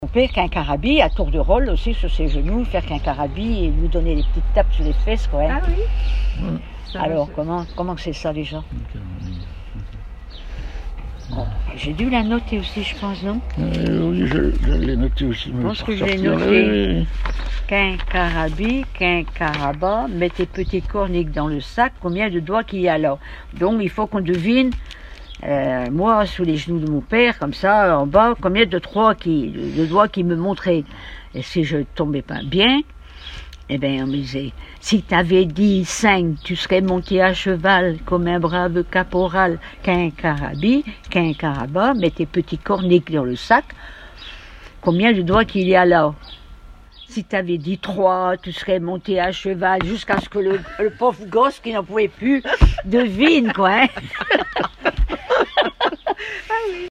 enfantine : comptine ; formulette enfantine : jeu des doigts
Témoignages et chansons
Pièce musicale inédite